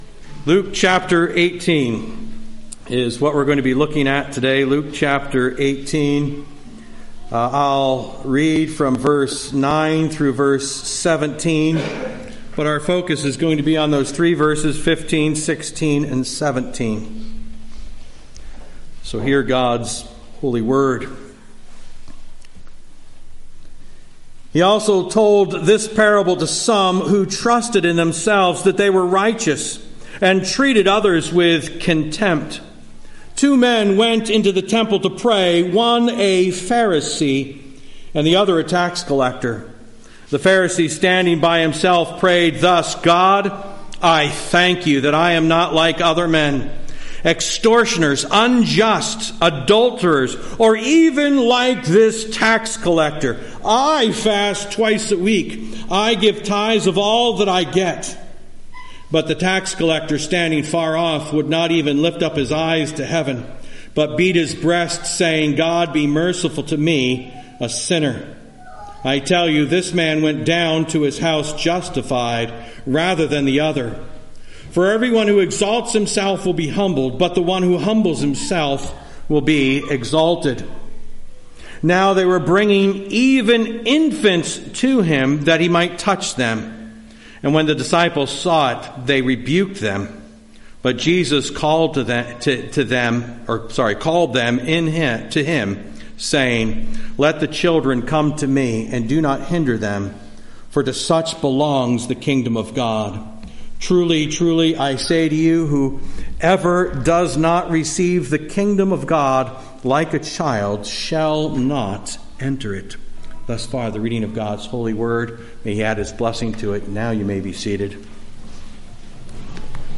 Morning Sermon